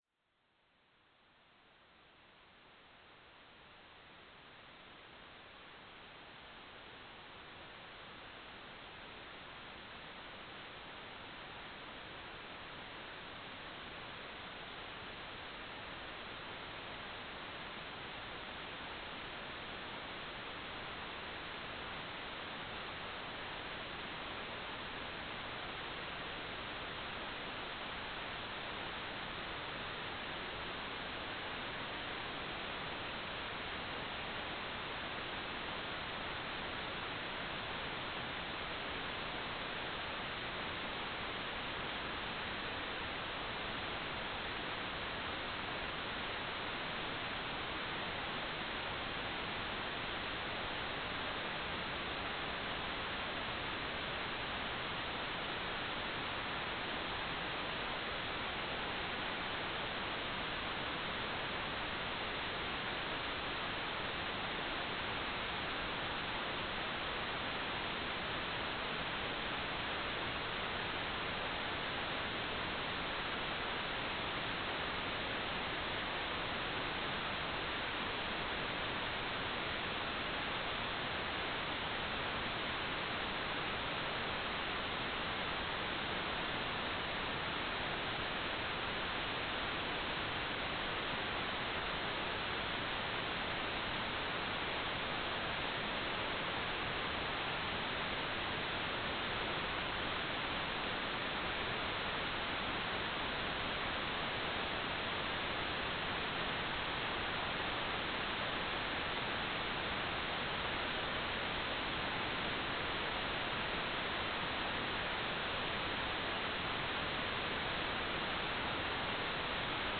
"transmitter_description": "CW Beacon",
"transmitter_mode": "CW",